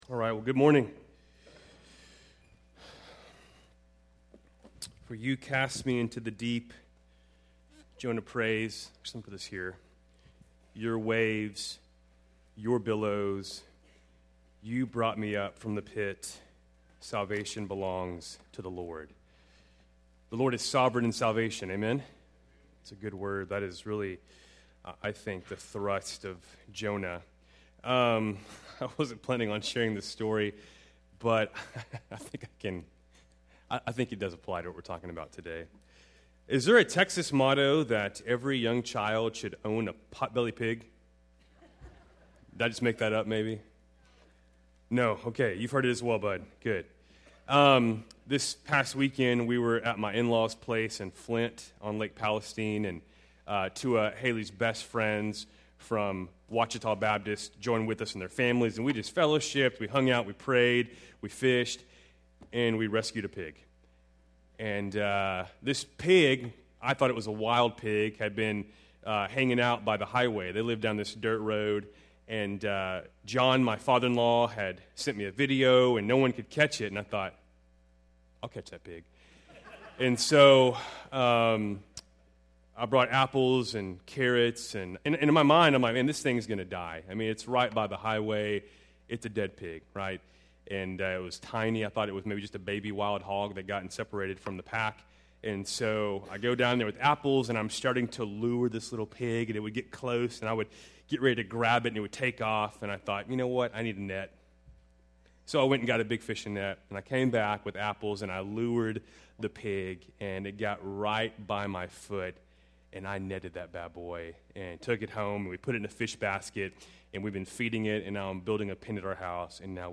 Keltys Worship Service, June 27, 2021